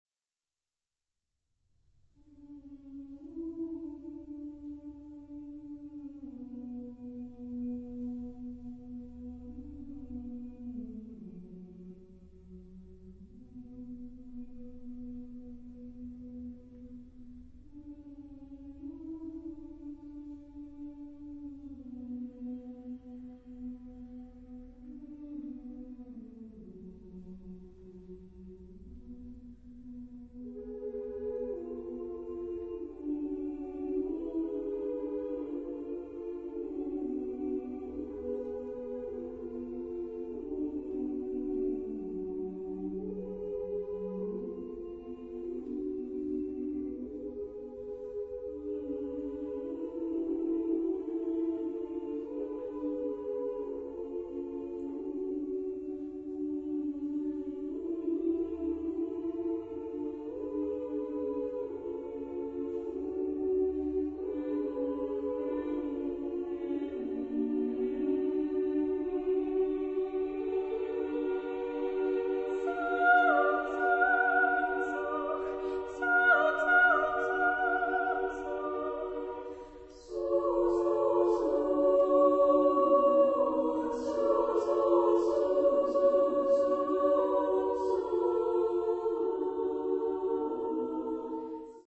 Genre-Style-Form: Secular ; Vocal piece ; Lullaby
Mood of the piece: calm
Type of Choir: SMA  (3 women voices )
Soloist(s): Soprano (1)  (1 soloist(s))